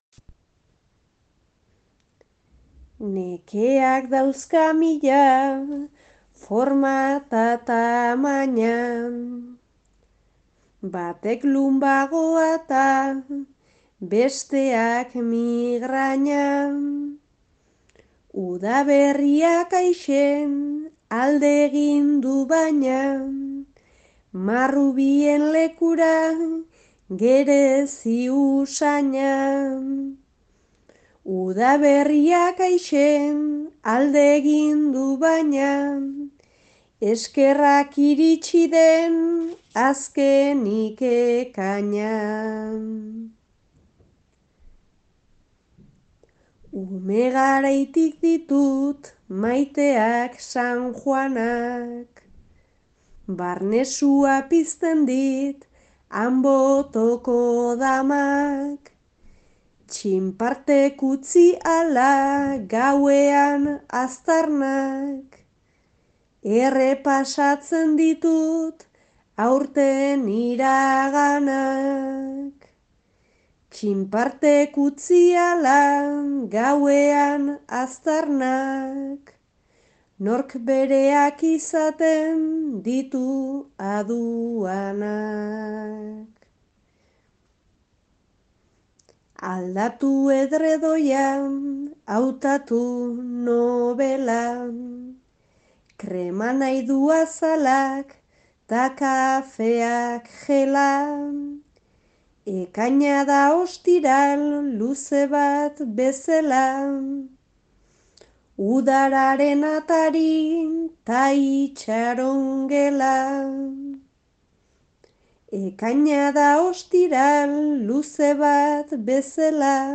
lau bertsoko sorta